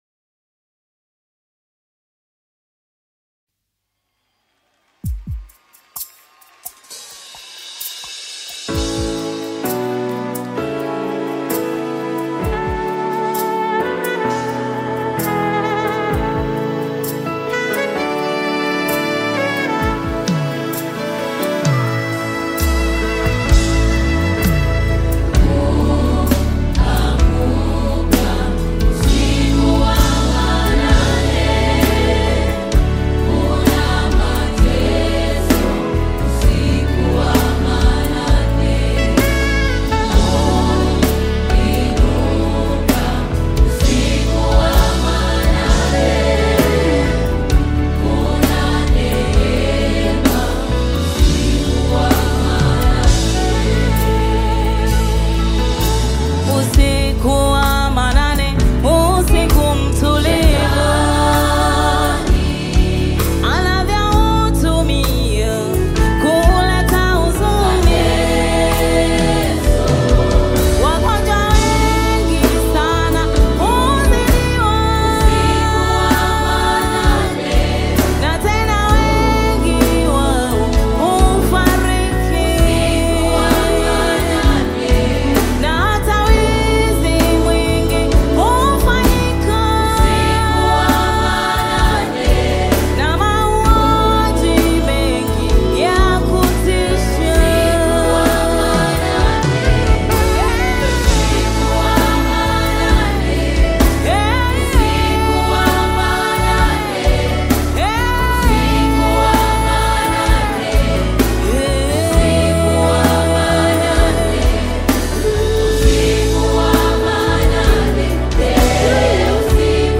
Tanzanian gospel choir